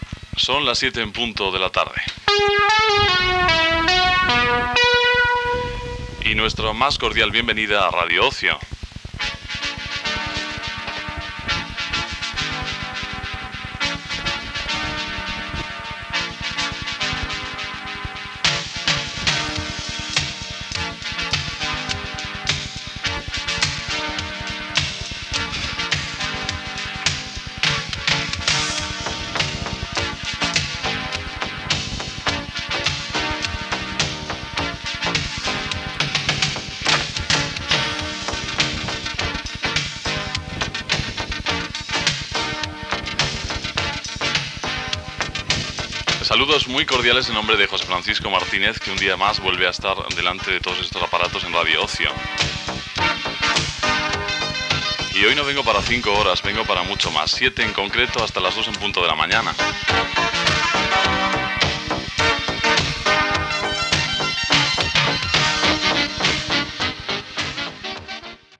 3235c7ddca23d9ce281029d637d6893f2fd56266.mp3 Títol Radio Ocio Emissora Radio Ocio Titularitat Privada local Descripció Hora i inici de la programació. Gènere radiofònic Musical